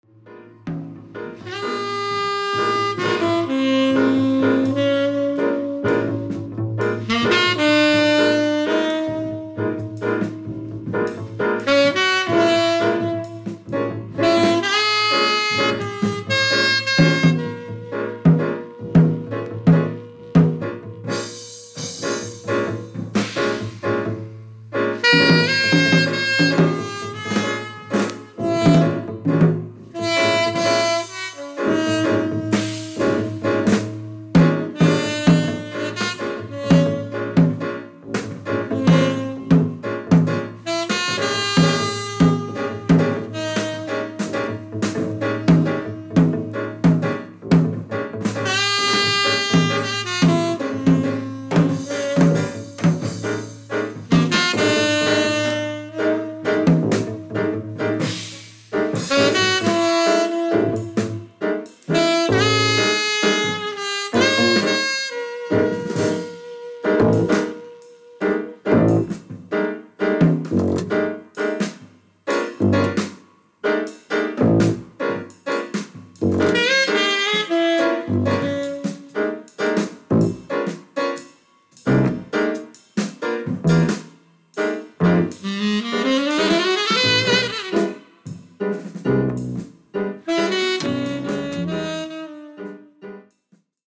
Drums
Bass
Piano
Saxophone, Clarinet